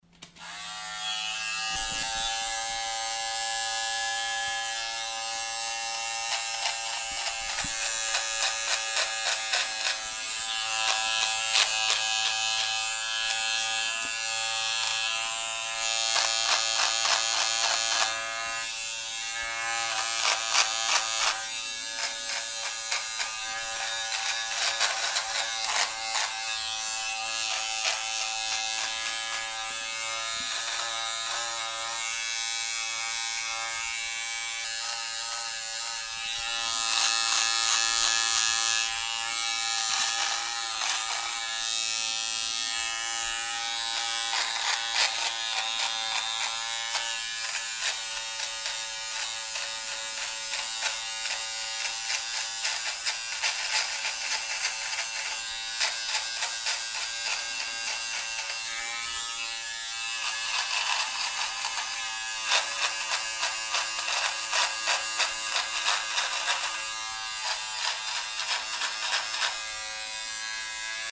Звуки триммера